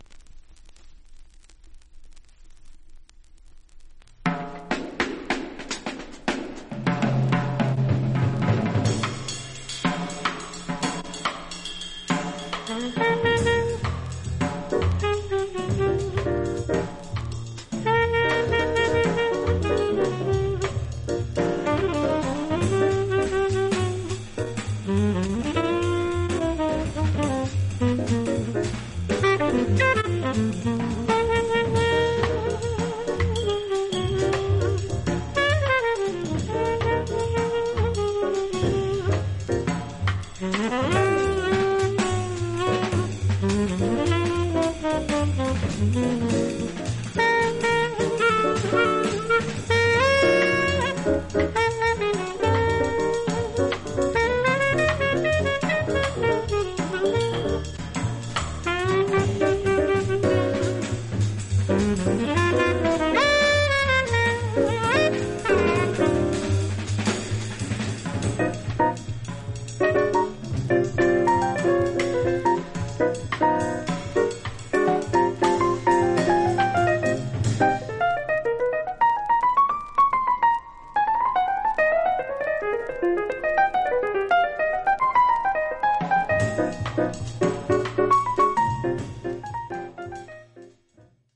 64年録音の未発表音源。
※盤面薄いビニール焼けがあり無音部では薄いバックグラウンドノイズが入ります。
実際のレコードからのサンプル↓ 試聴はこちら： サンプル≪mp3≫